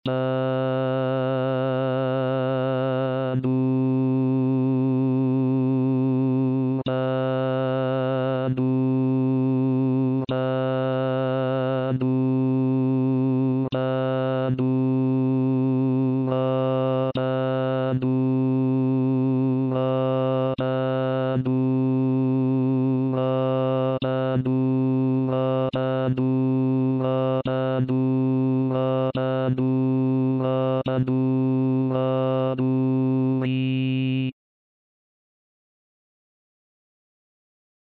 UOMINI